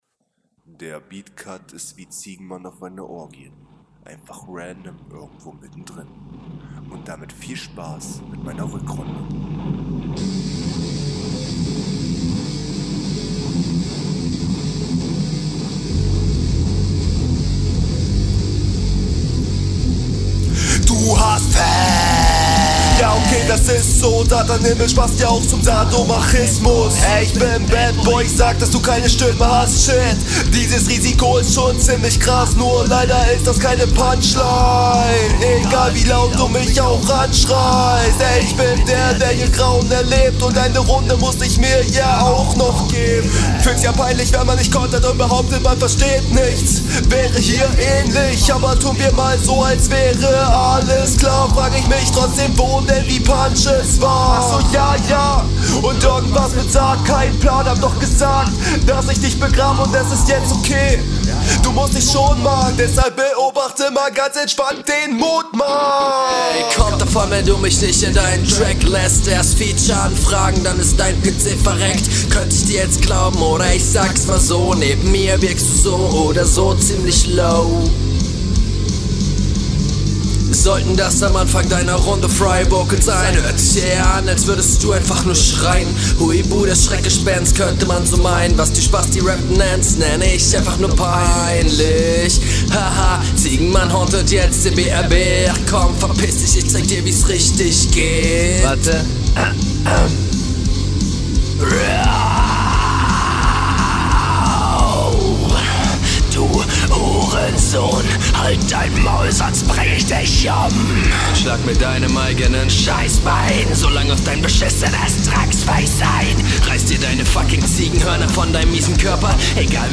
Verständlicher, aber flowlich leider auch nicht so routiniert drauf.